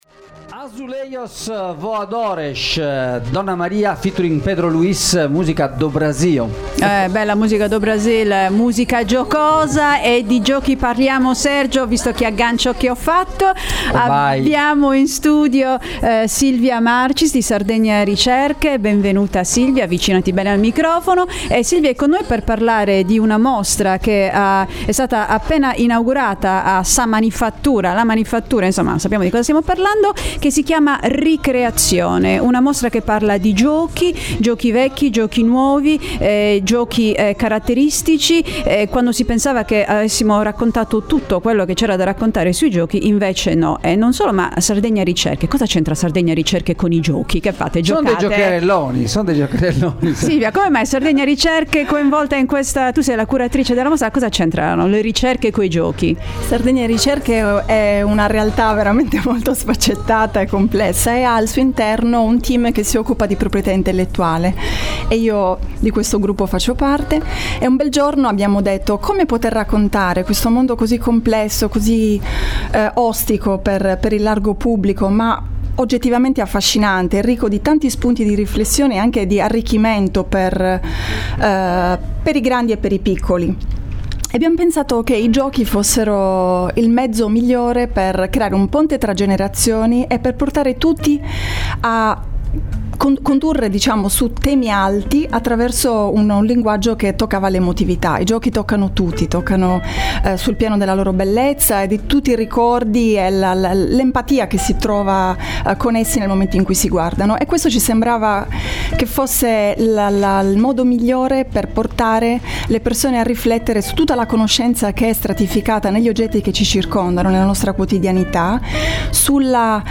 Ri-Creazione a Sa Manifattura! - intervista